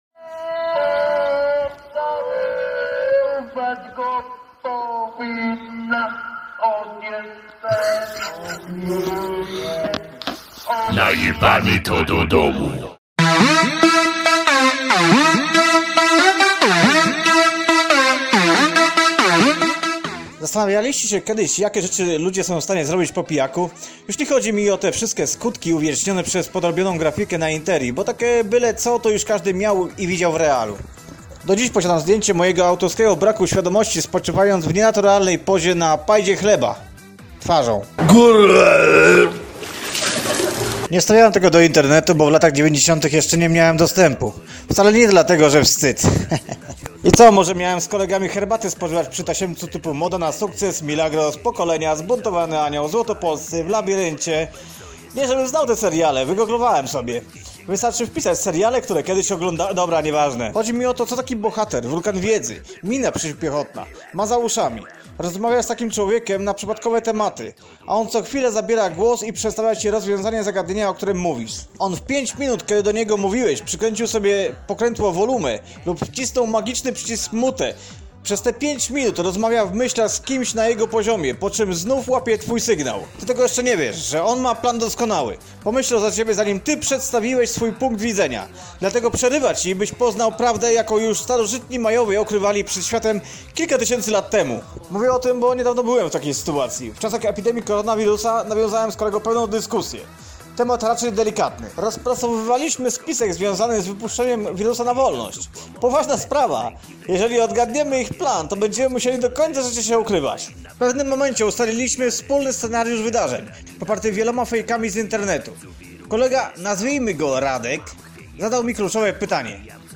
Czytał sam